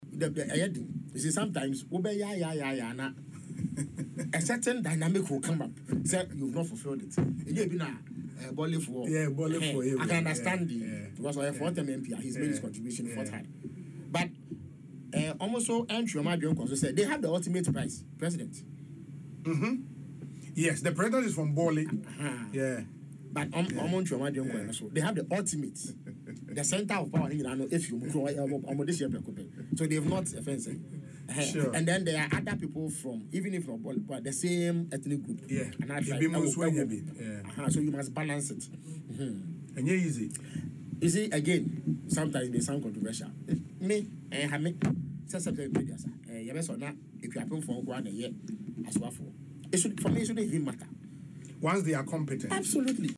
In an interview on Asempa FM Ekosii Sen, the Abura Asebu Kwamankese MP stated that was an ultimate prize they must cherish.